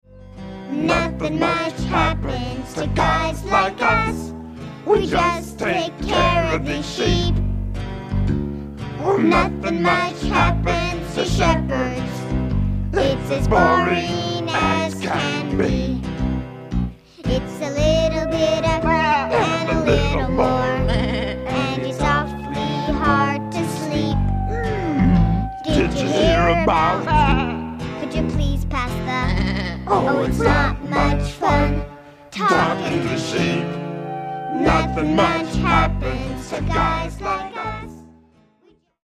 Selected Song Samples